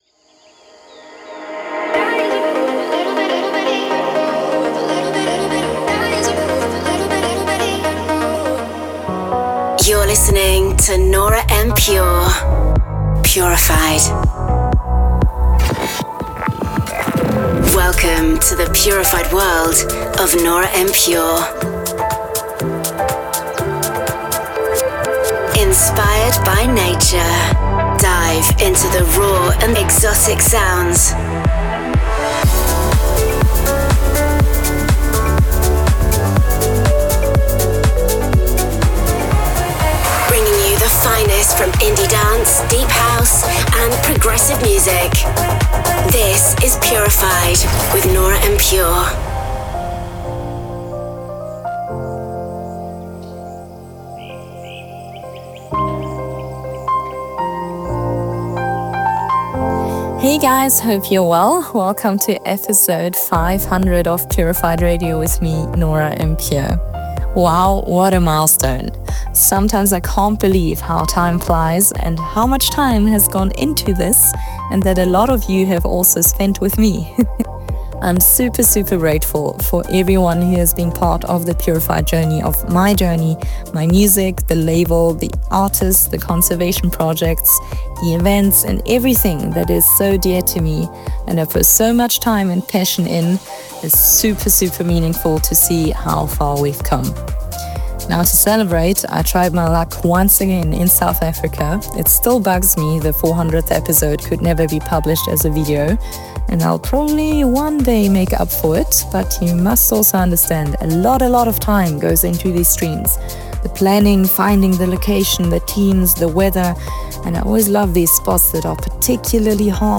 Genre: Progressive house; Duration